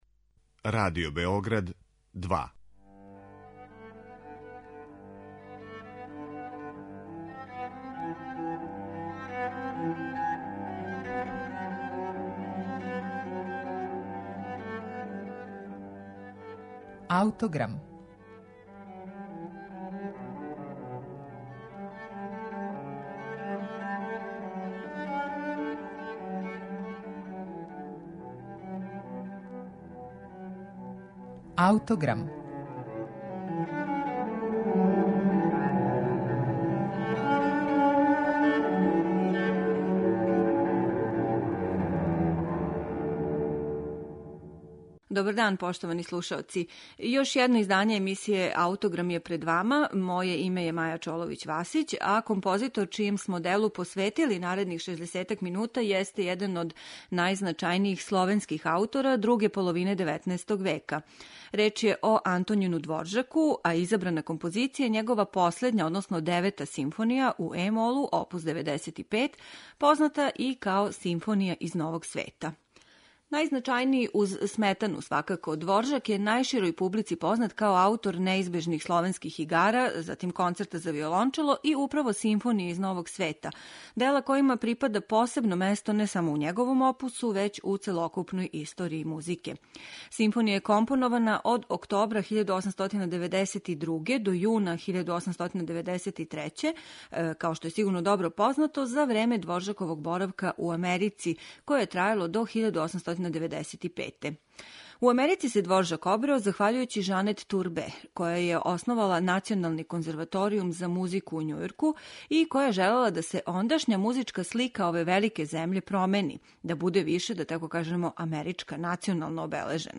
Једно од најпознатијих и најпопуларнијих симфонијских остварења свих времена, данас ћете у Аутограму слушати у извођењу оркестра Краљевске филхармоније, којим диригује Паво Јерви.